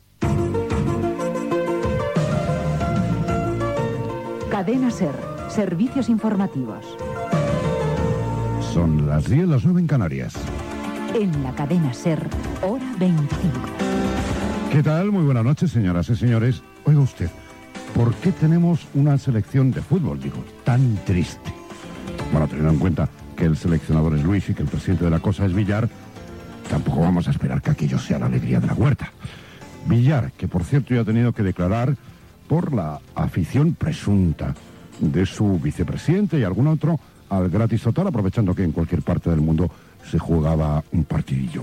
Indicatiu dels serveis informatius, careta del programa, la selecció espanyola de futbol.
Informatiu